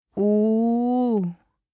알림음(효과음) + 벨소리
알림음 8_WomanOh2.mp3